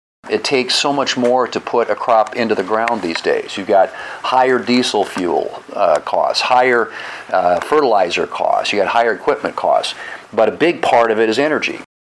And here’s South Dakota Senator John Thune.